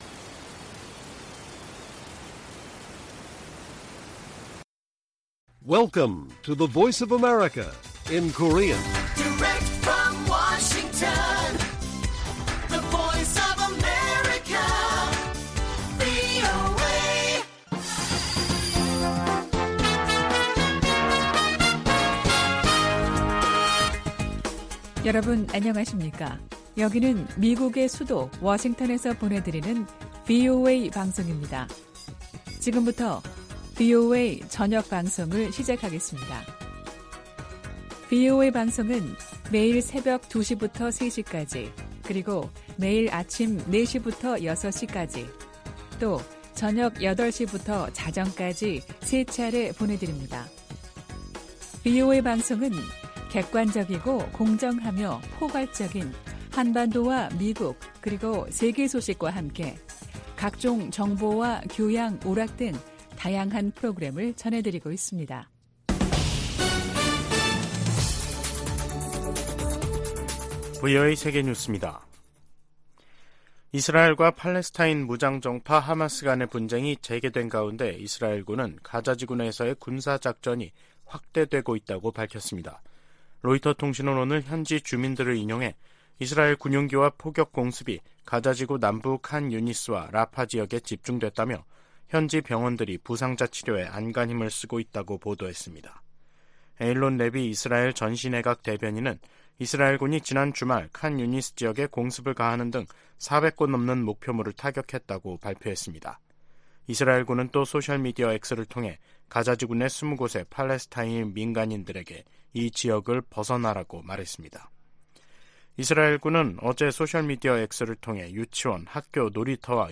VOA 한국어 간판 뉴스 프로그램 '뉴스 투데이', 2023년 12월 4일 1부 방송입니다. 북한에 이어 한국도 첫 군사정찰위성 발사에 성공하면서 남북한 간 위성 경쟁이 치열해질 전망입니다.